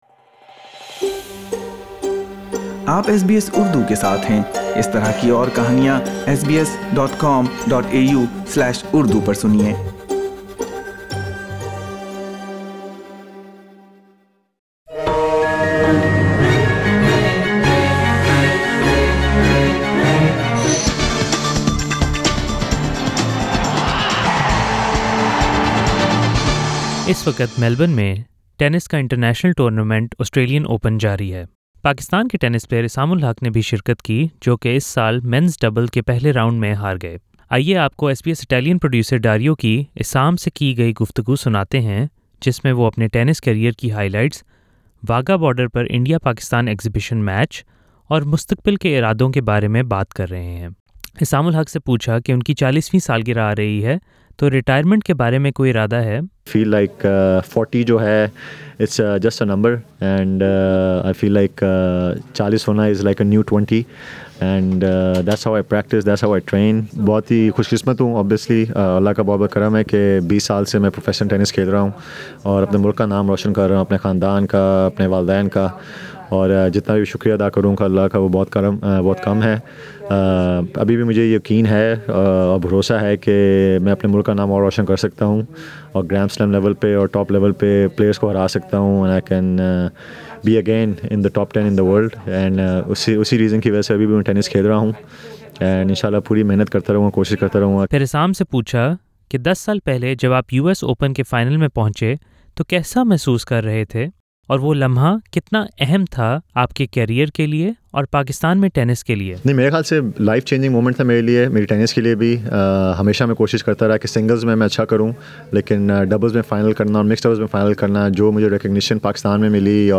Aisam-Ul-Haq talking to SBS at Australian Open 2020.